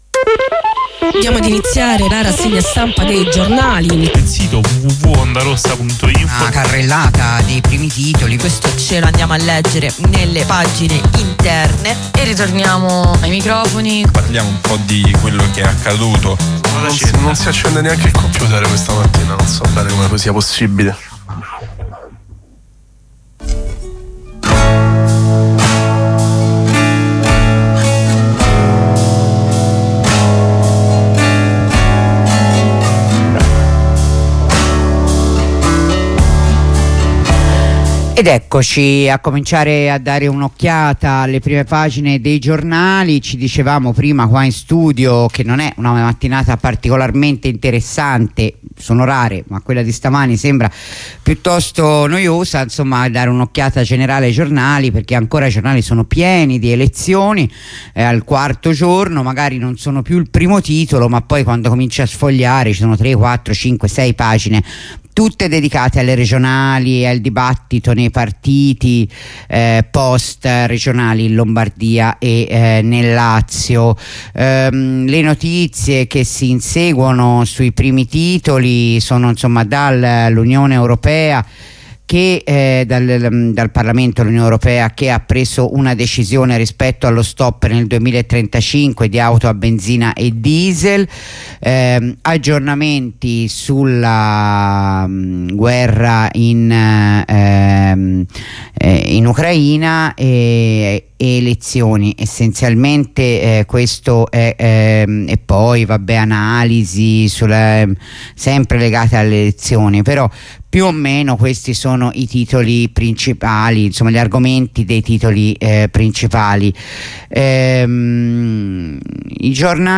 La rassegna stampa di Radio Onda Rossa